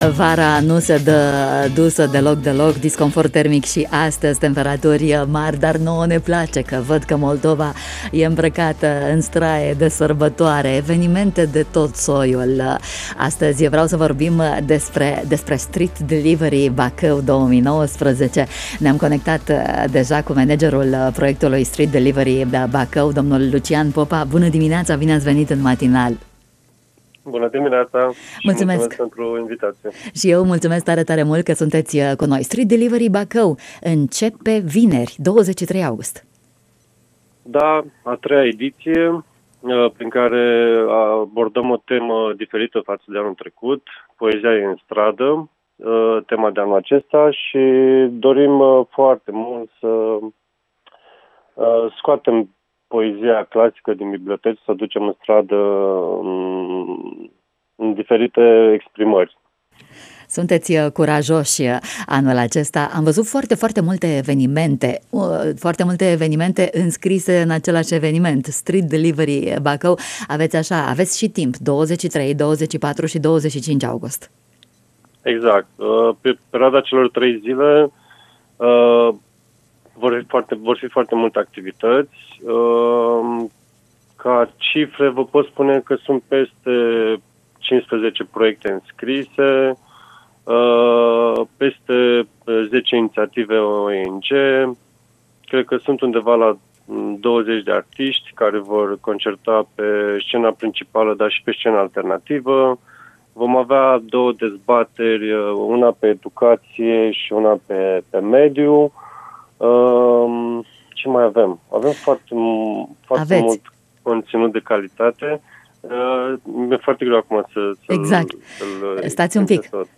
în matinalul Radio România Iaşi